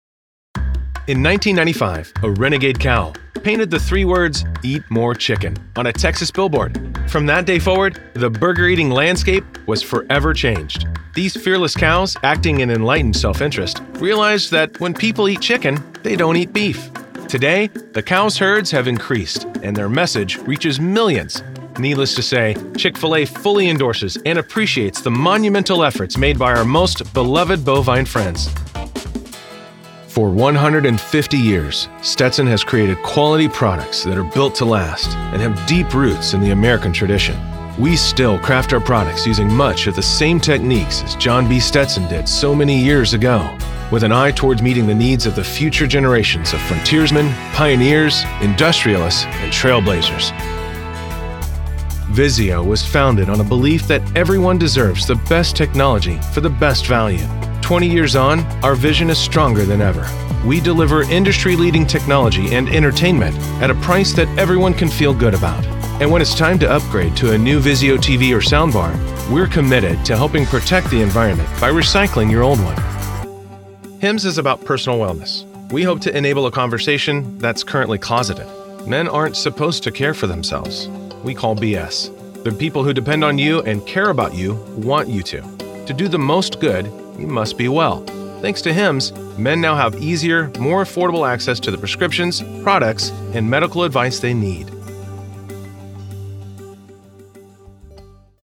Male
English (North American)
Yng Adult (18-29), Adult (30-50)
My voice has been described as warm, genuine, authentic, trustworthy, authoritative, knowledgeable, inviting, engaging, encouraging, high-energy, believable, down-to-earth, informative, sincere, big, booming, and relatable.
Corporate Demo
Words that describe my voice are warm, genuine, trustworthy.